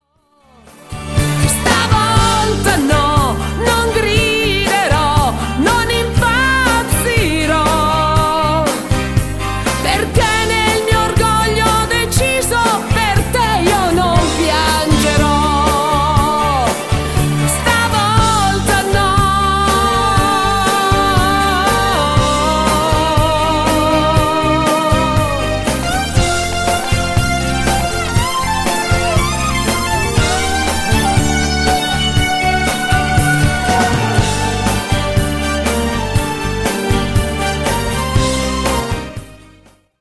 MODERATO  (3.25)